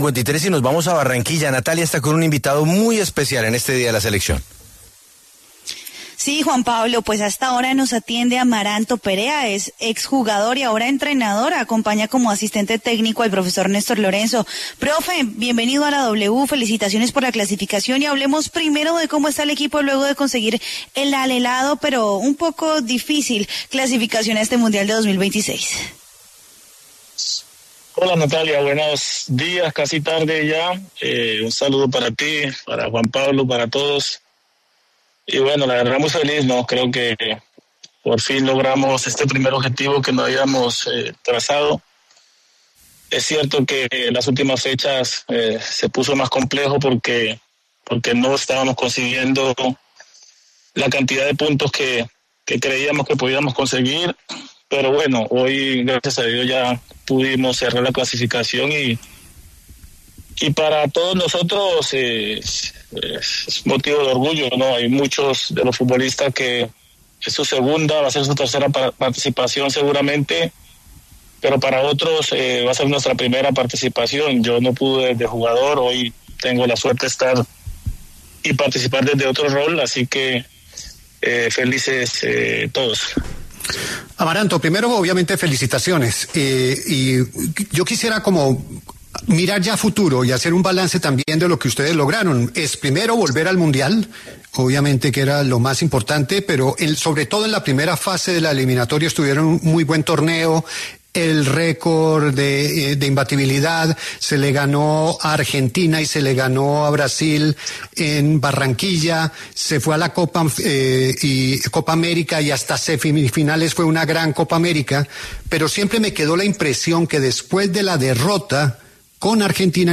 El exfutbolista Amaranto Perea habló en La W sobre la clasificación de la Selección Colombia al Mundial 2026.